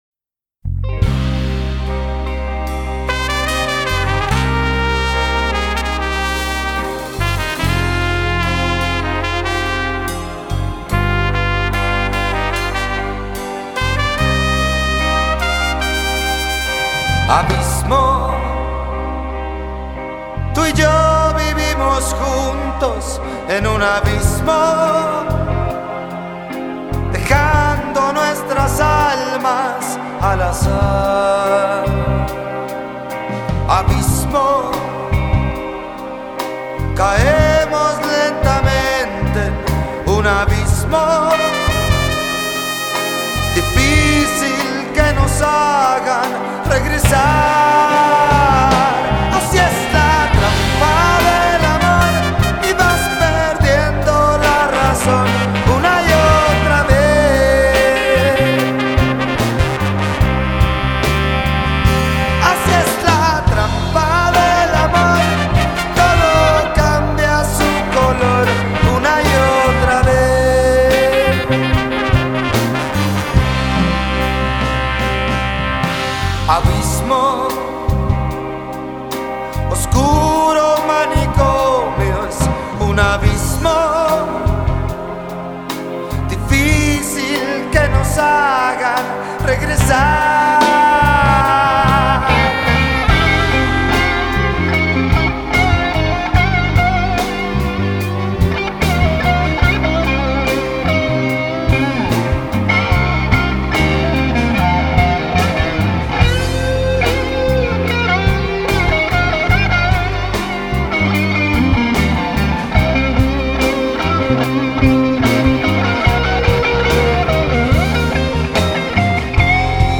presentamos la grabación en vivo